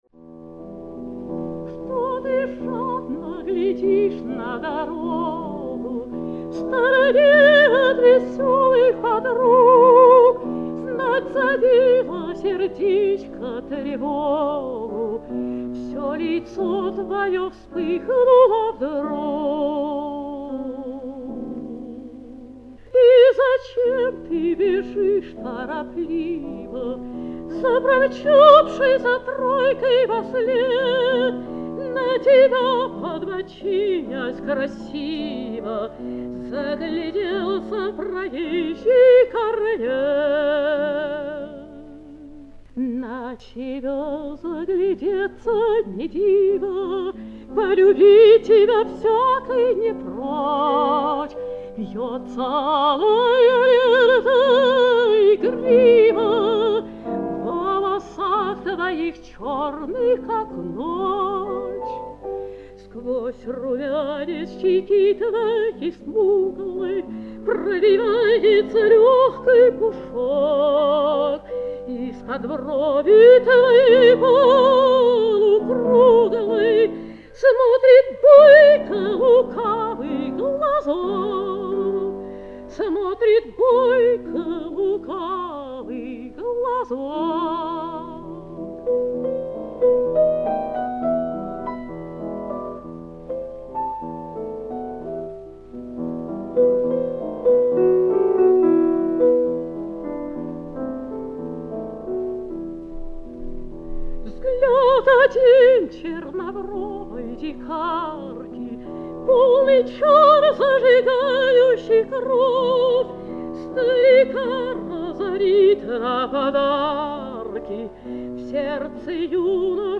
Прослушивание аудиозаписи романса «Что ты жадно глядишь на дорогу» в исполнении Н. А. Обуховой.